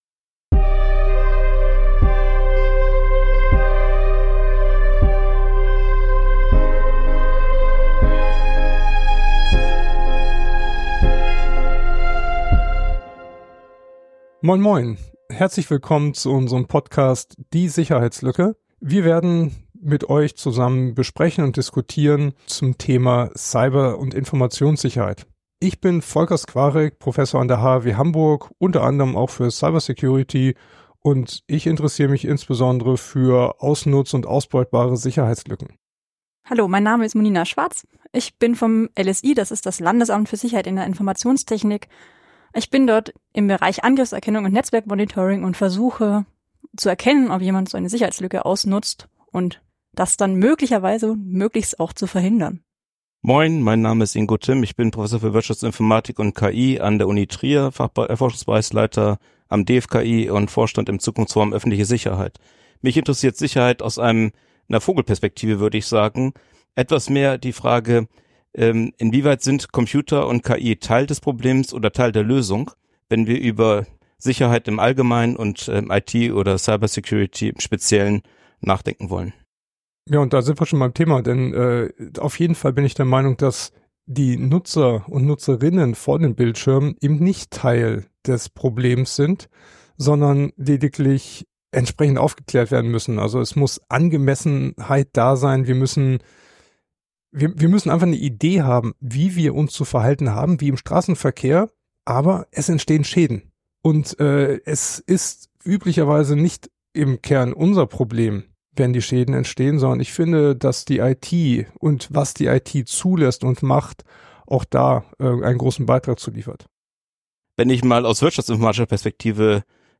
Der Podcast macht Cyber Security greifbar und verbindet fundierte Inhalte mit unterhaltsamen Gesprächen.